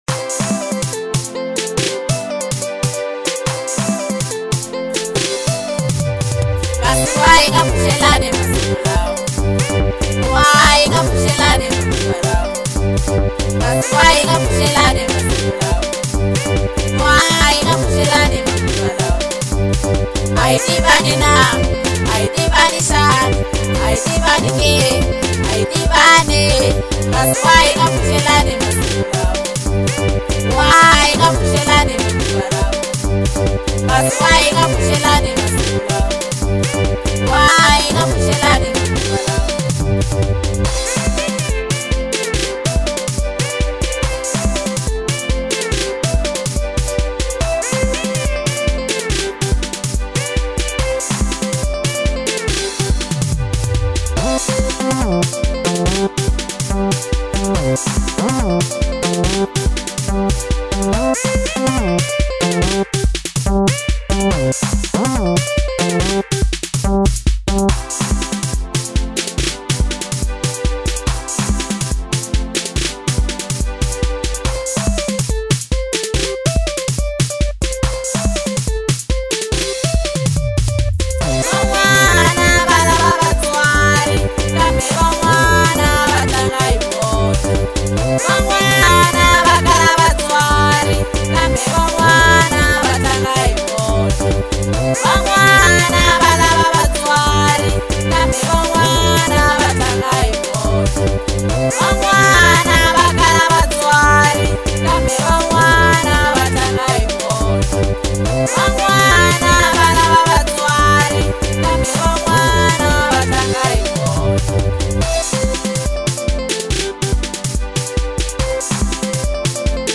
African Kwasa